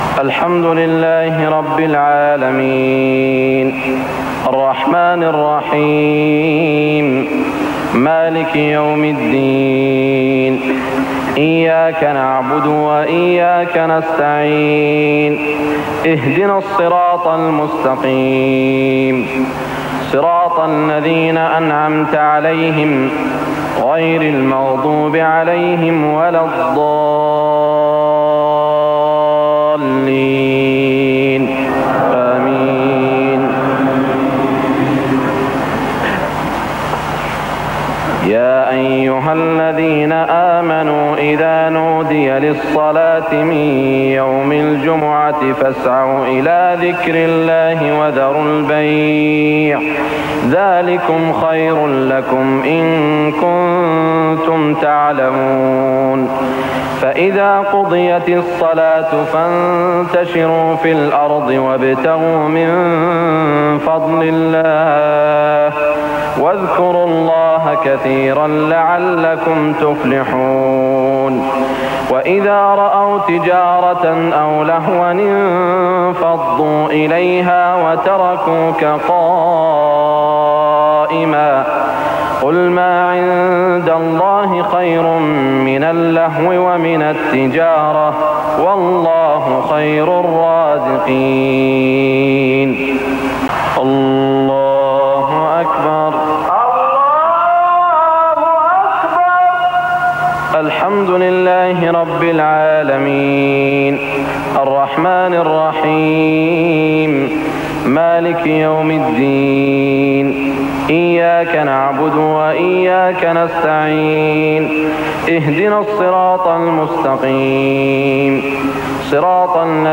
صلاة المغرب 1412هـ من سورتي الجمعة و المطففين > 1412 🕋 > الفروض - تلاوات الحرمين